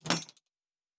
UI Tight 28.wav